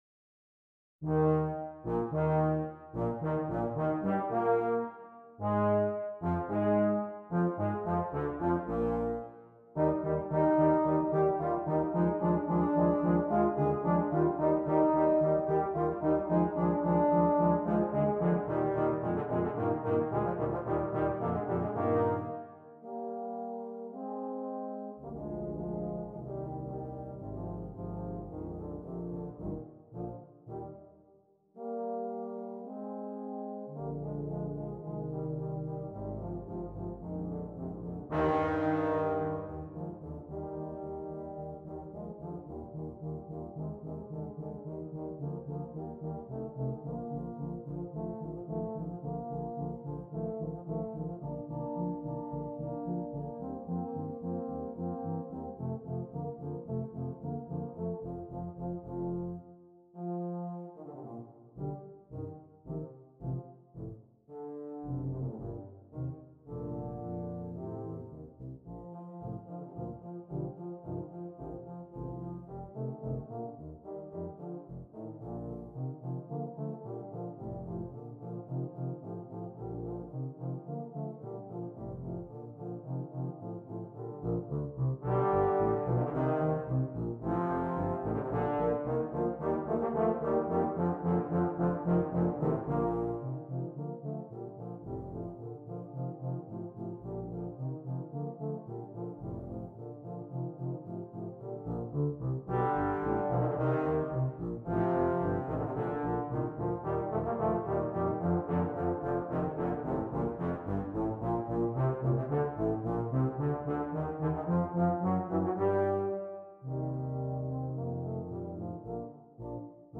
2 Euphonium, 2 Tuba
low brass ensemble consisting of 2 euphoniums and 2 tubas
All parts are interesting and exciting.